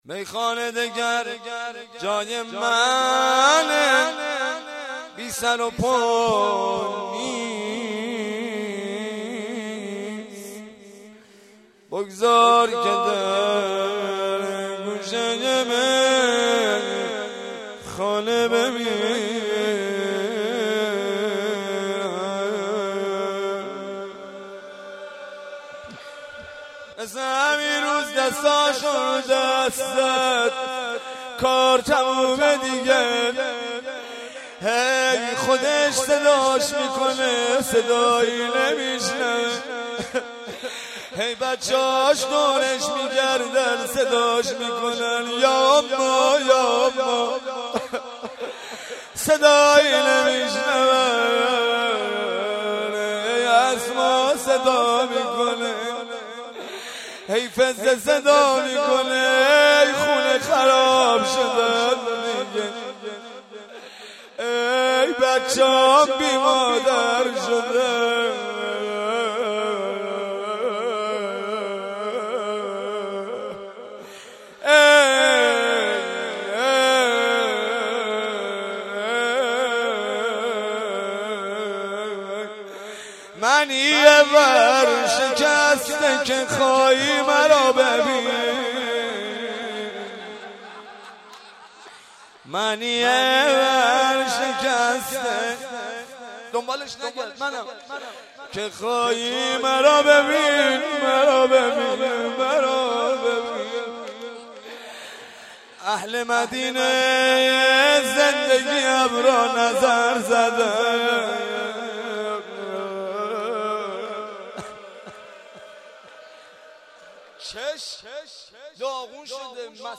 روضه
شور